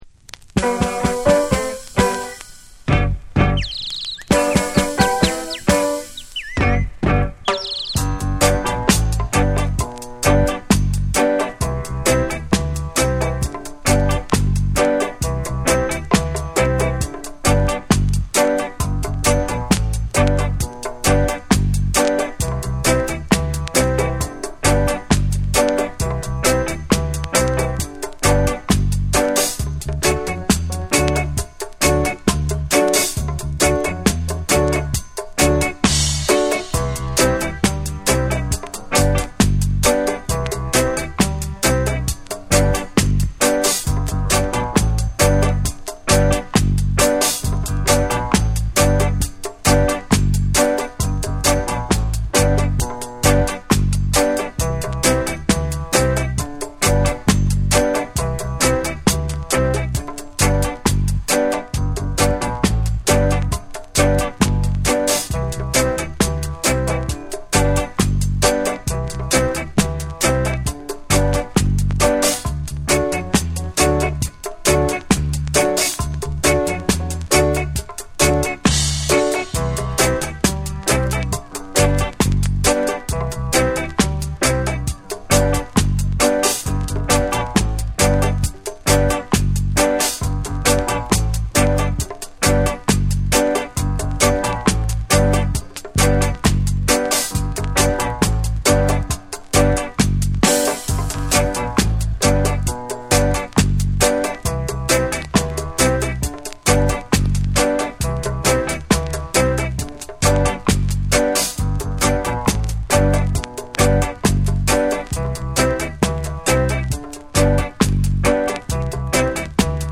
A5に円周キズ、ノイズ入る箇所あり。
REGGAE & DUB